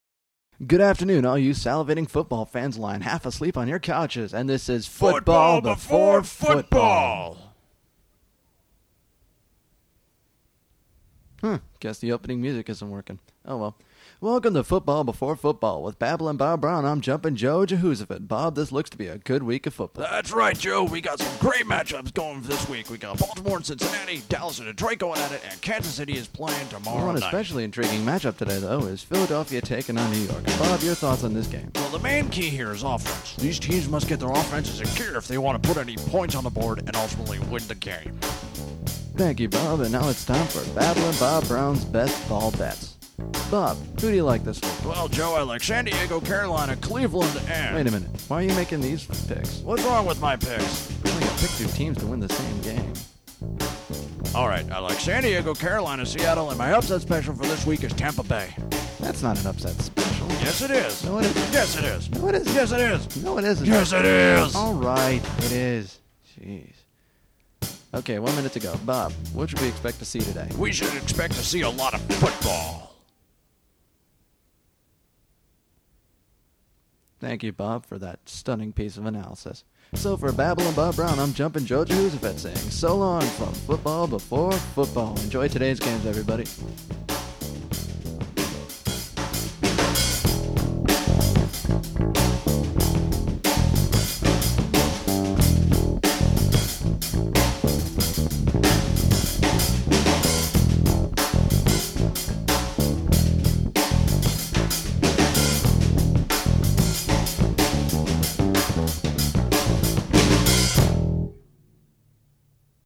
Production, mixing, all instruments and voices by me.
Recorded on a 4-track at SUNY Purchase in 2003.